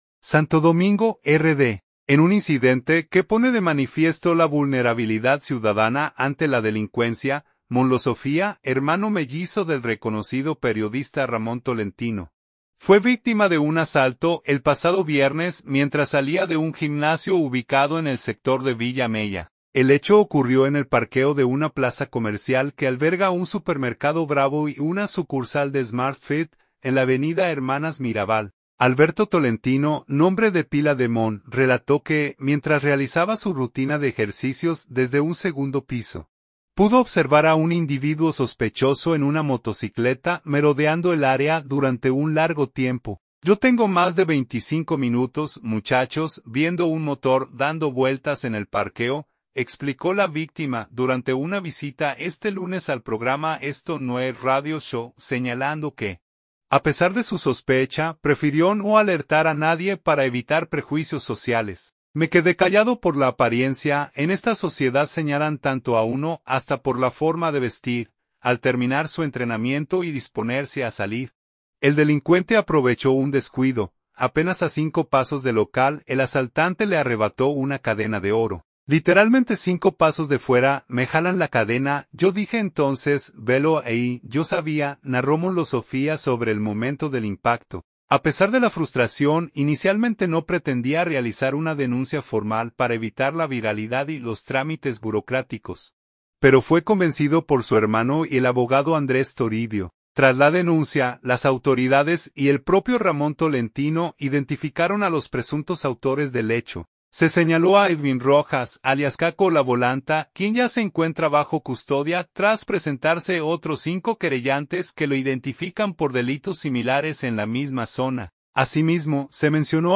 "Yo tengo más de veinticinco minutos, muchachos, viendo un motor dando vueltas en el parqueo", explicó la víctima durante una visita este lunes al programa Esto No es Radio Show, señalando que, a pesar de su sospecha, prefirió no alertar a nadie para evitar prejuicios sociales.